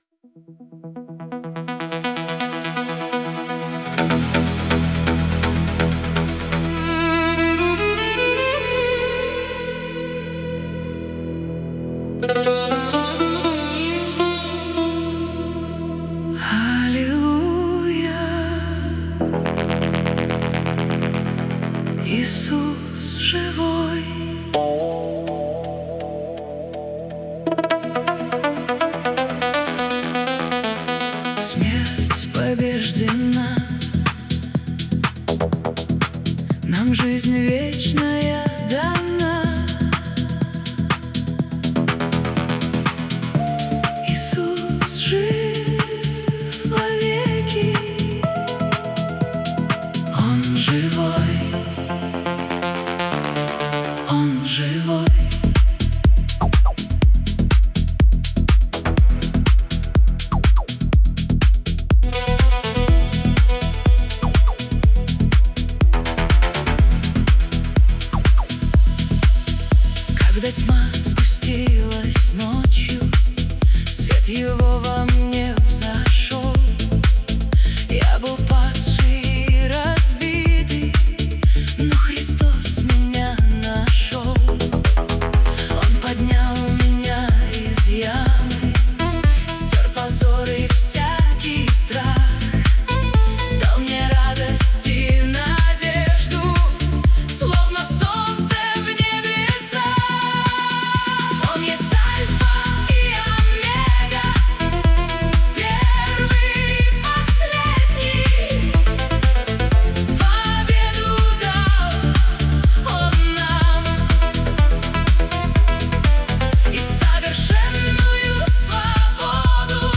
песня ai
6 просмотров 26 прослушиваний 2 скачивания BPM: 124